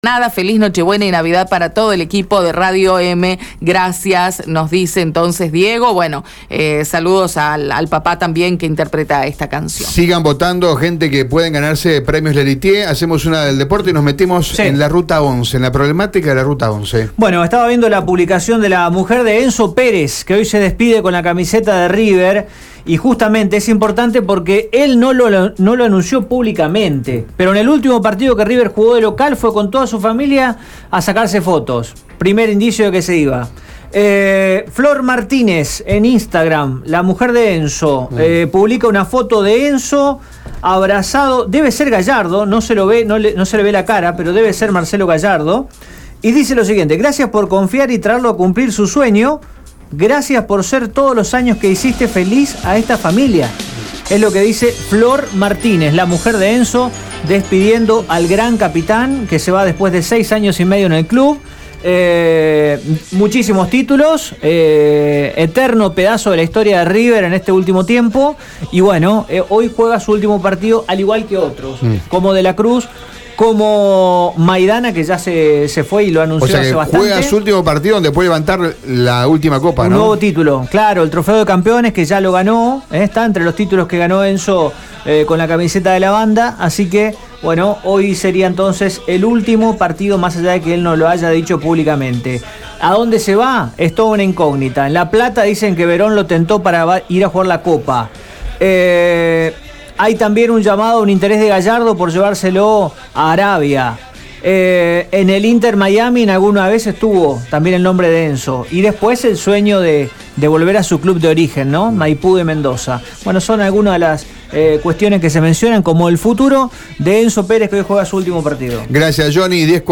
Ante esta situación, y un reclamo que crece día a día en busca de que mejoren la ruta, Radio EME dialogó con el periodista